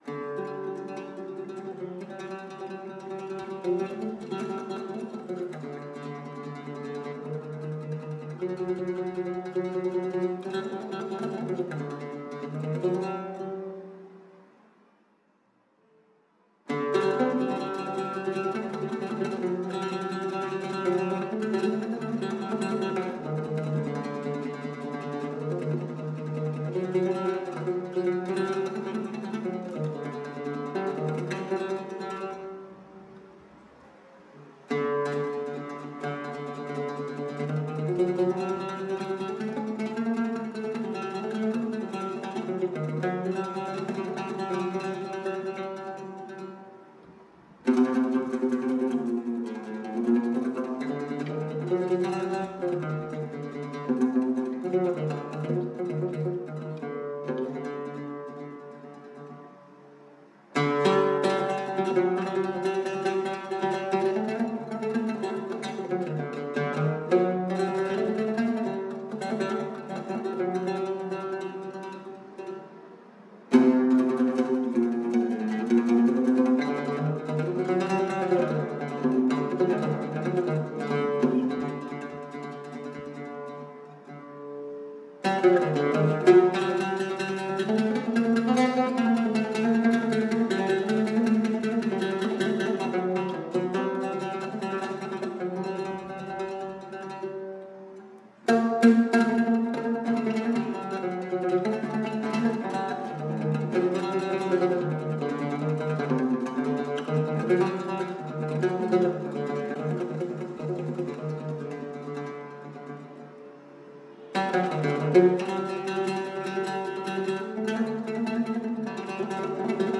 ヒジャーズ旋法アザーンの旋律（ウードによる）